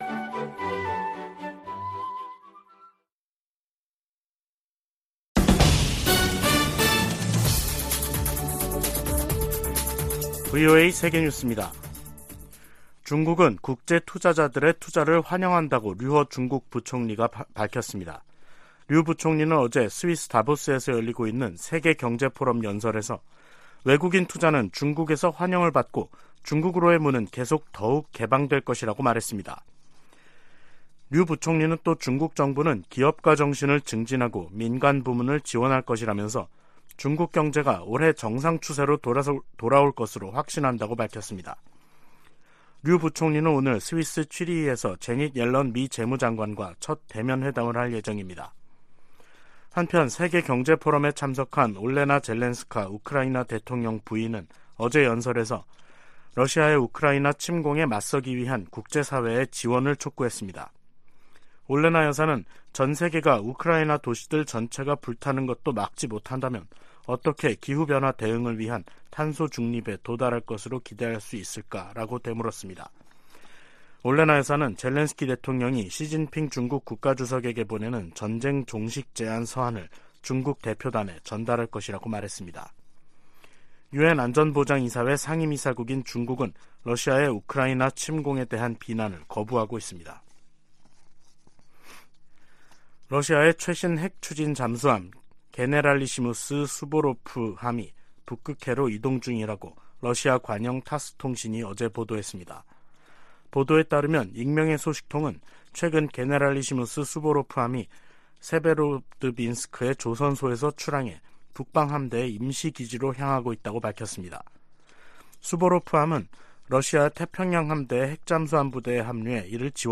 VOA 한국어 간판 뉴스 프로그램 '뉴스 투데이', 2023년 1월 18일 2부 방송입니다. 미 국방부는 로이드 오스틴 국방장관이 곧 한국을 방문할 것이라고 밝혔습니다. 한국 통일부가 올해 북한과의 대화 물꼬를 트겠다는 의지를 밝히고 있으나 북한이 호응할 가능성은 크지 않다는 관측이 나오고 있습니다.